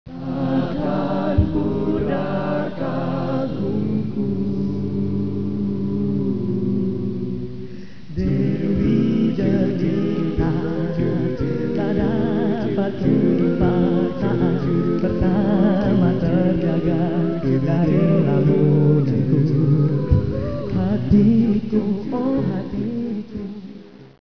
A Cappella Advisory : No Instruments